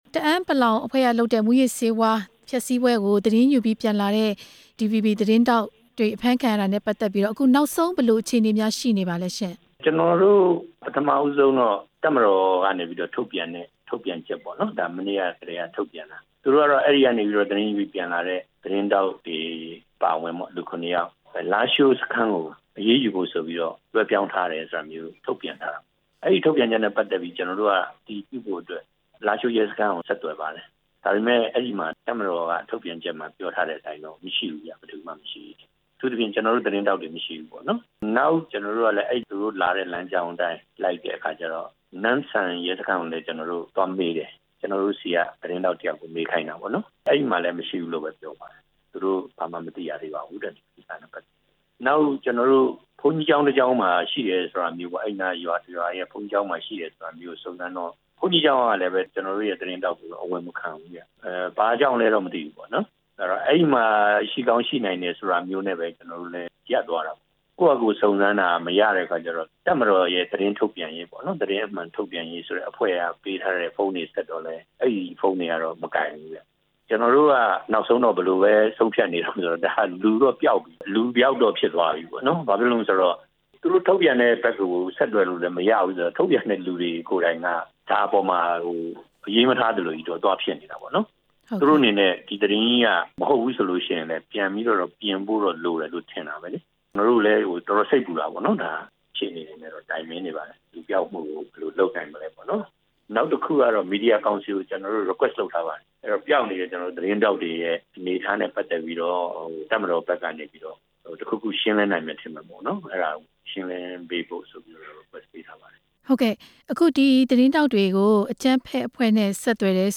ဖမ်းဆီးခံသတင်းထောက်တွေအရေး ဒီဗွီဘီသတင်းဌာနနဲ့ မေးမြန်းချက်